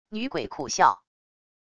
女鬼苦笑wav音频